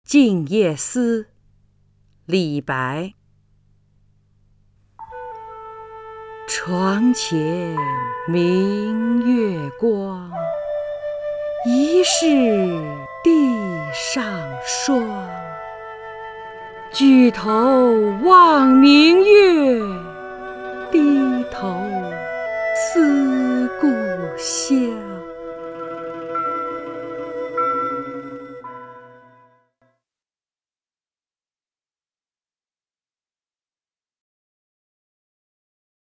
张筠英朗诵：《静夜思》(（唐）李白) （唐）李白 名家朗诵欣赏张筠英 语文PLUS